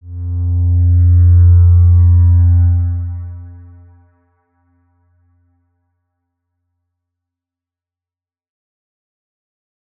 X_Windwistle-F1-ff.wav